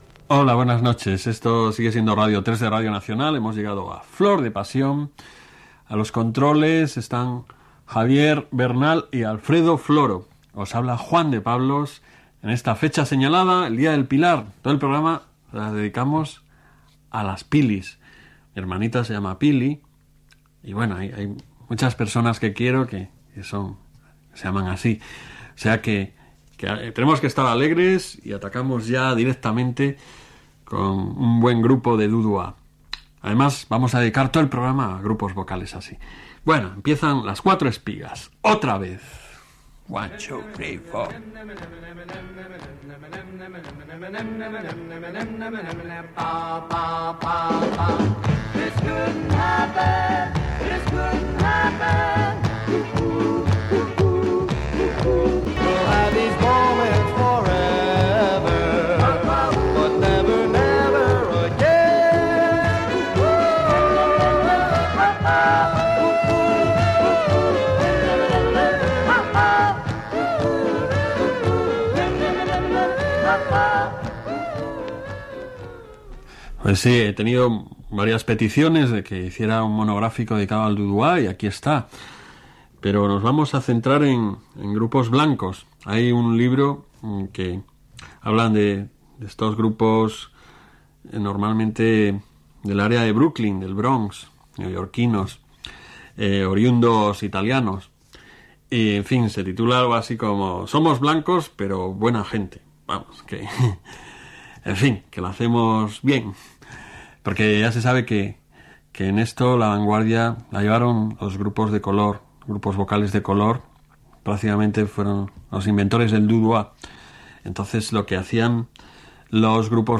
Identificació de l'emissora i del programa, equip, data, tema musical, el gènere del "du dua" interpretat per grups de cantants blancs del Bronx de Nova York, tema musical
Musical